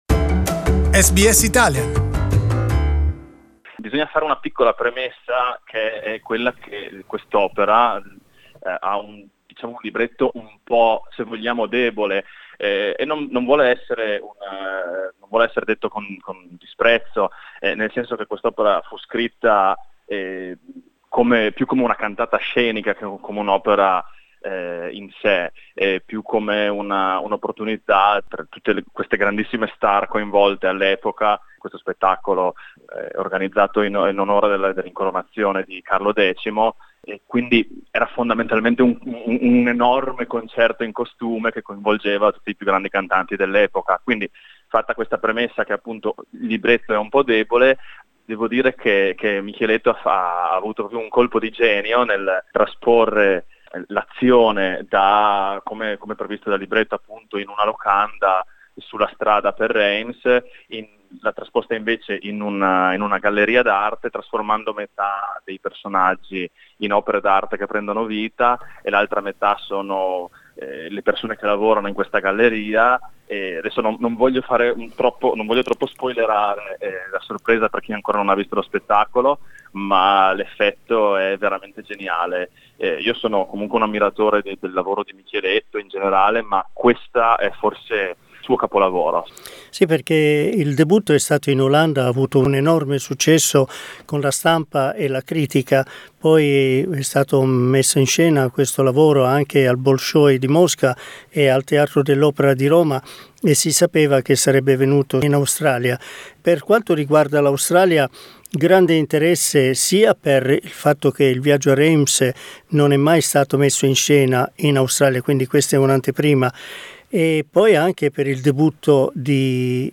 In this interview he talks about the opera staged at the Arts Centre in Melbourne directed by Damiano Michieletto that has been enthusiastically acclaimed by critics and audiences.